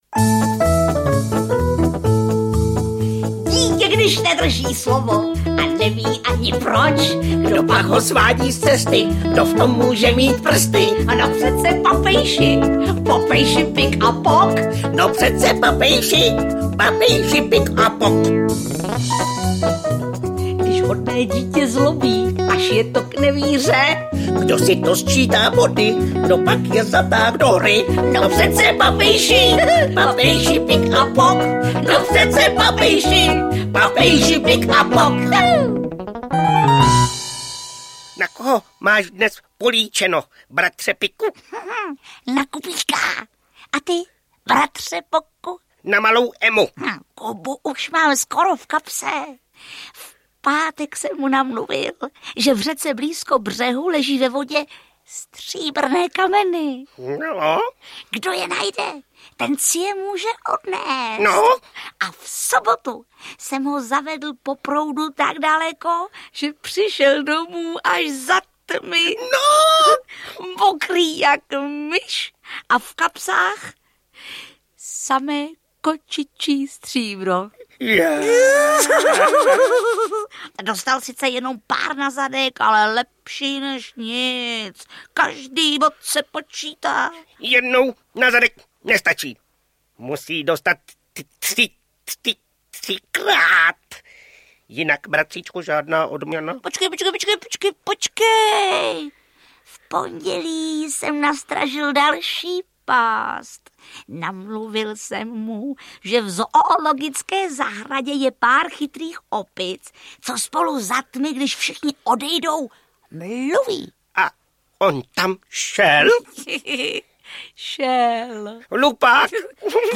Jak Kuba vyzrál na Papejše audiokniha
Ukázka z knihy
Ale na malého Kubu si rozhodně nepřijdou... Úsměvnou pohádku s písničkami napsala Viola Fischerová.
jak-kuba-vyzral-na-papejse-audiokniha